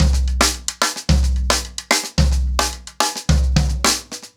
TimeToRun-110BPM.1.wav